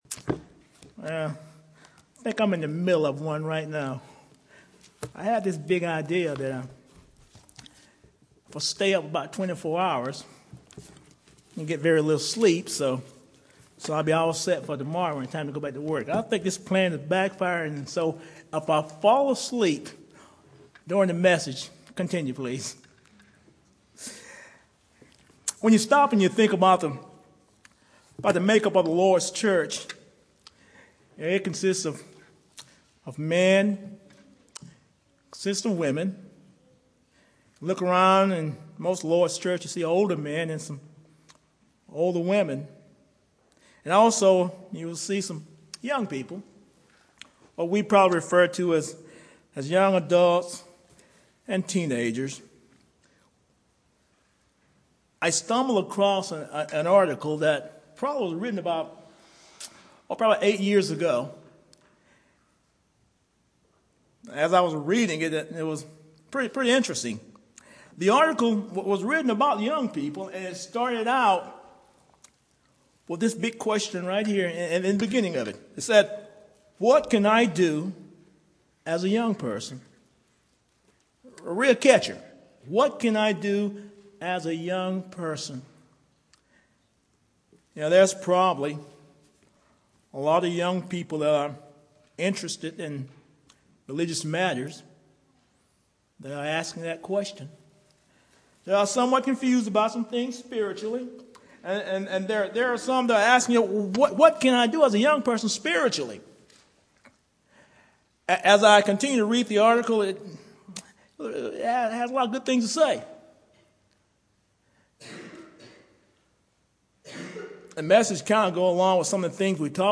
For our annual Lectureship weekend, we thought to give our younger men some experience in preparing and delivering lessons for the edification of the church. We chose the theme of “The Most Holy Faith” as a good start for this effort.